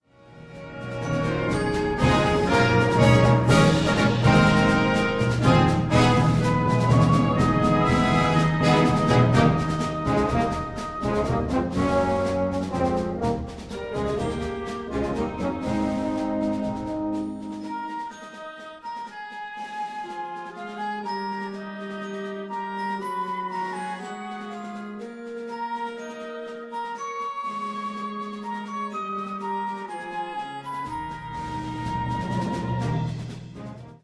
Kategorie Blasorchester/HaFaBra
Unterkategorie moderne populäre Blasmusik
Besetzung Ha (Blasorchester)